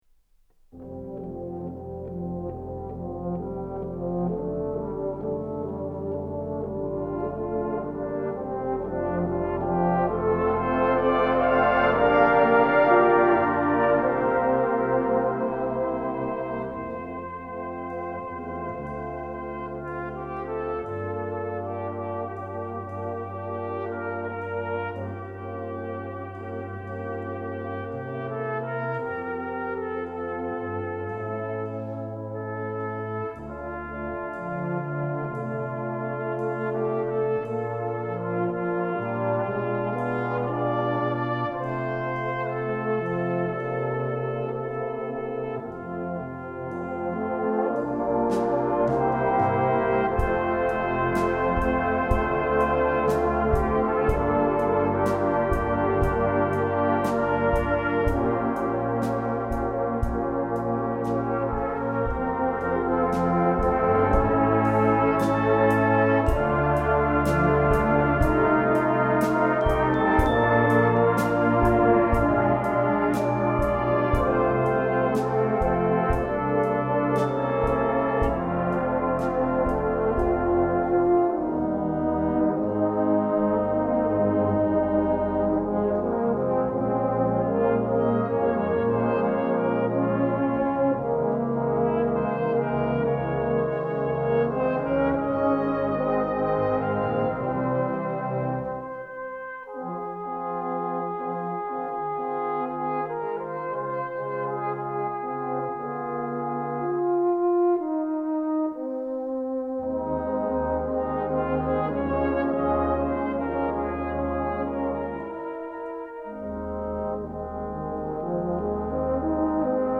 Such Love played by Bellshill Salvation Army Band: